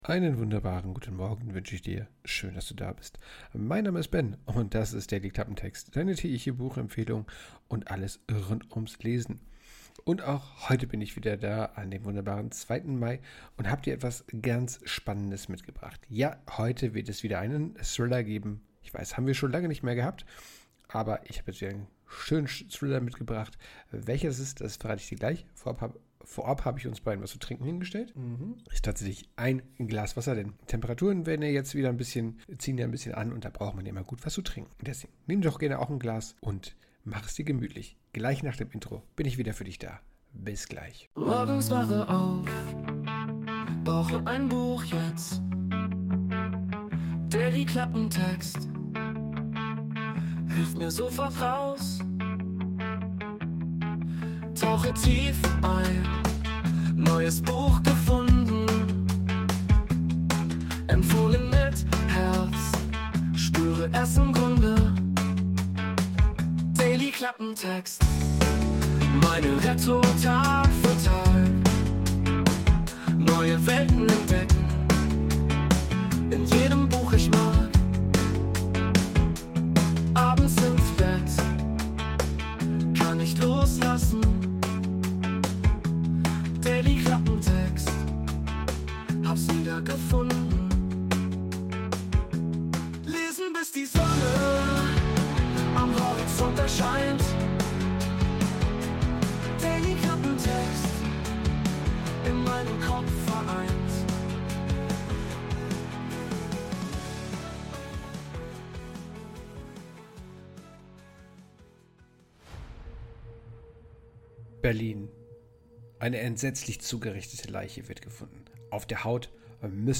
Intromusik: Wurde mit der KI Sonos erstellt.